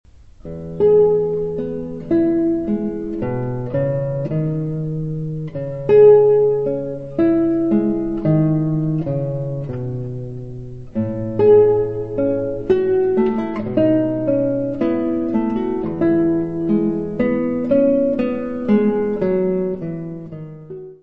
: stereo; 12 cm
Área:  Música Clássica
Andante espressivo.